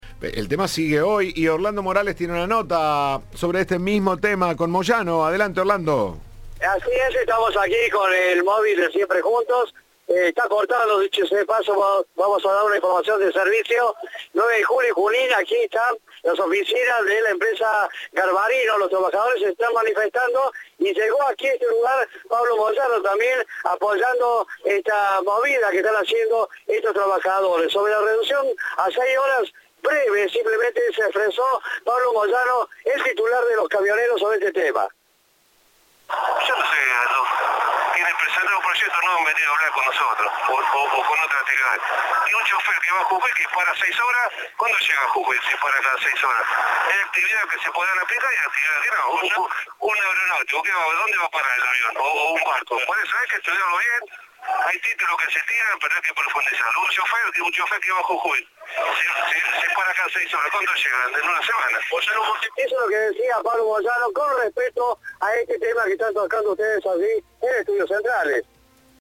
Por último, el titular del gremio de los camioneros, Pablo Moyano, dijo a Cadena 3: "Hay que estudiarlo bien. Tienen que hablar con nosotros. Hay días que se podrá aplicar y días que no".